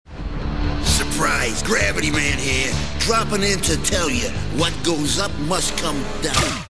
Gravity Man fails, as despite his speech that almost sounds like a
mad rhythm, he merely causes some rocks to fall down on them and then get blasted in the face.